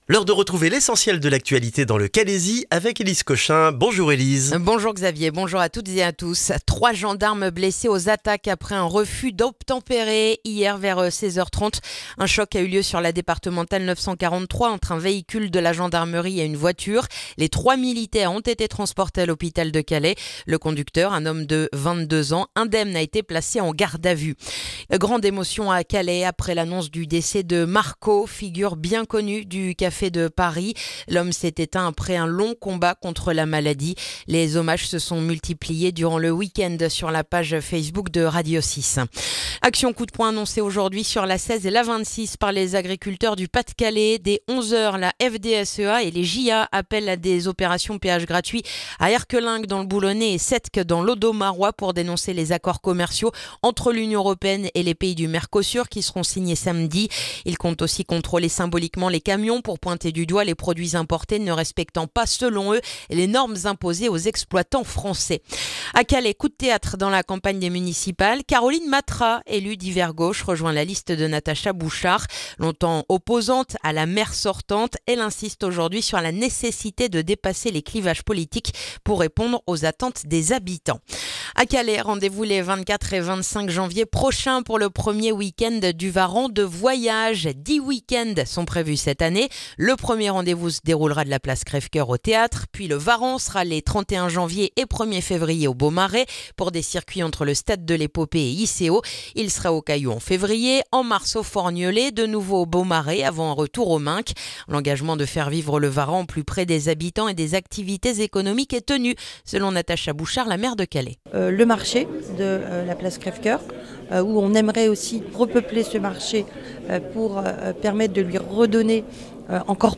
Le journal du lundi 12 janvier dans le calaisis